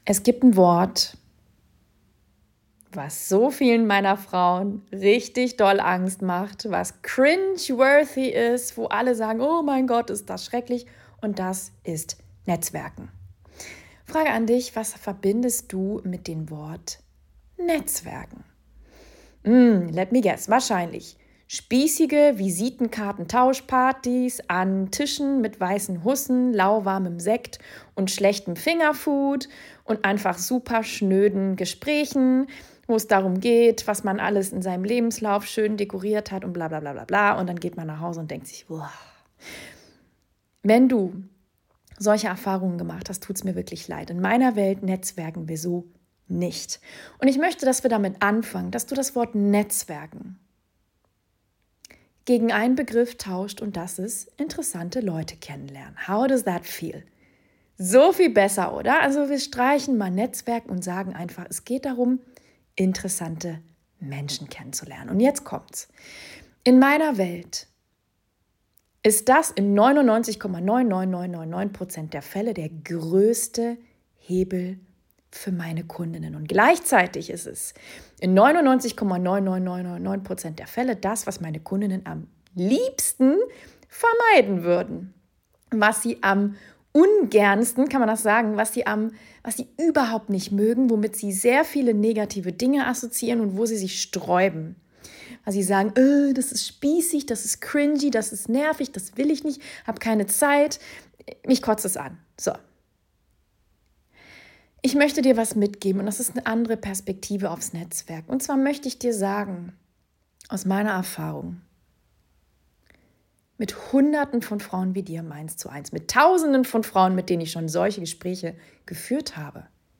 Real, raw und ungeschnitten.